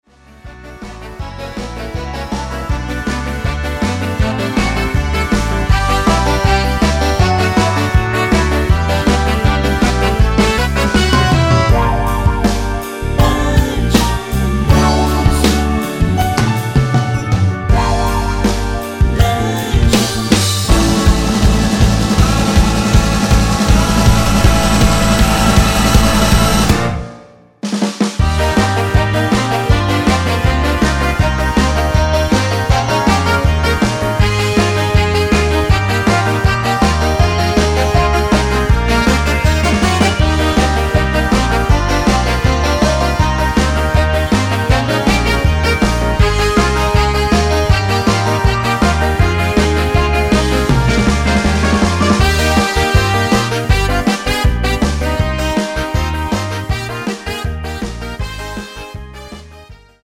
코러스 MR 중간 코러스 포함된 MR 입니다.(미리듣기 참조) 발매일 2002.09
앞부분30초, 뒷부분30초씩 편집해서 올려 드리고 있습니다.
중간에 음이 끈어지고 다시 나오는 이유는